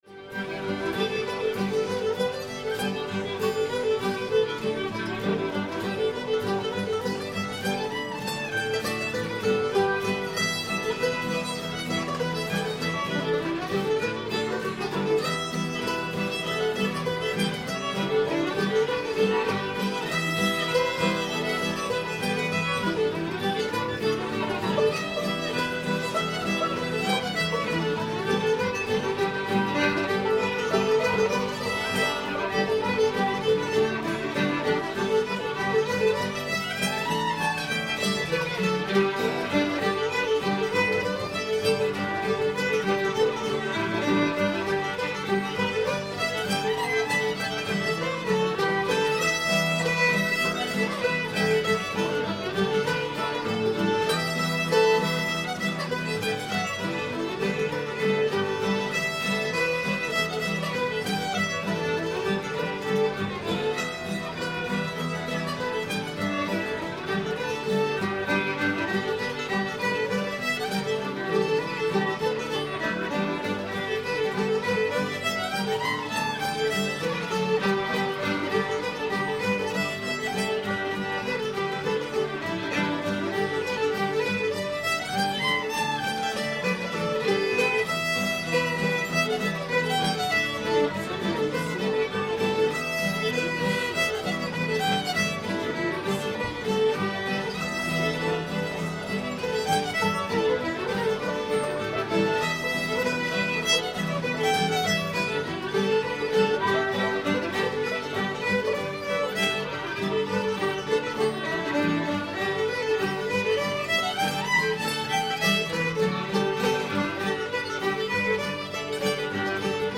jimmy in the swamp [G]